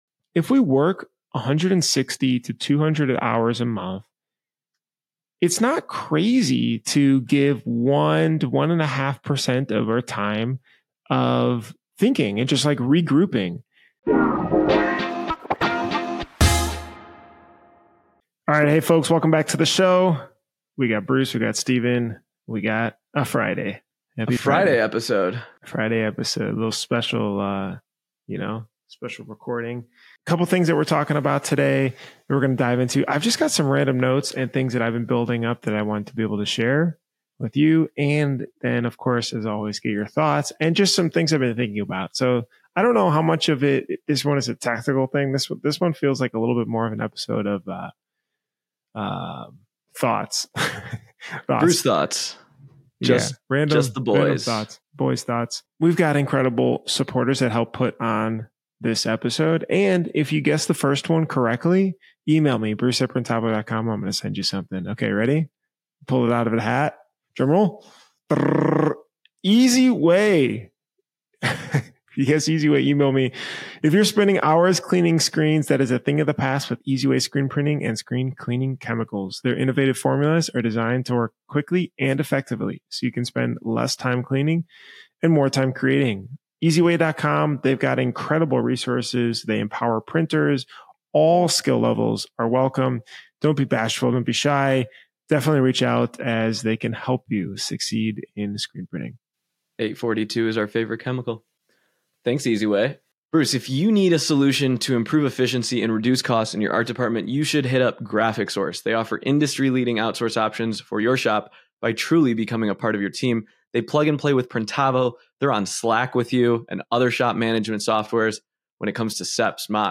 No guests, no fluff — just two print industry veterans breaking down the realities of running, growing, and surviving in the custom apparel world.